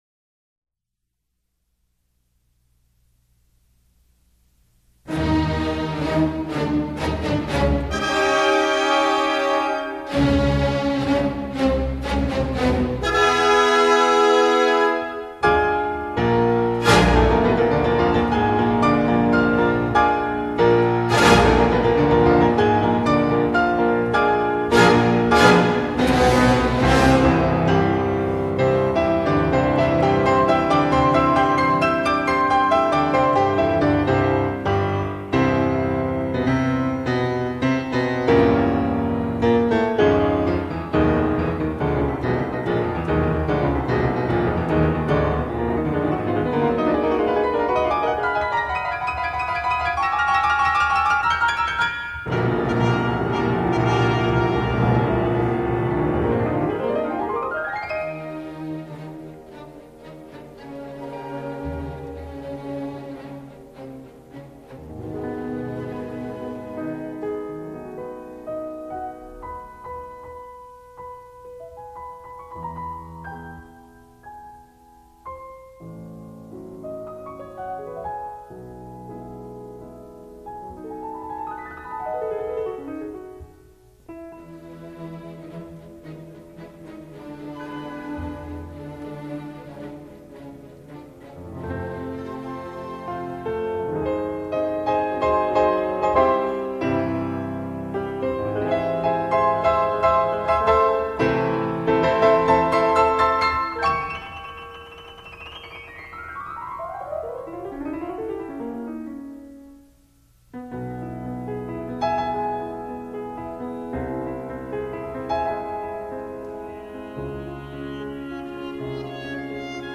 录音制式：ADD Stereo
以第一协奏曲而言，乐队部分直如海水碧波荡漾，就已神色逼人，叫人屏息而听。